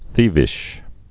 (thēvĭsh)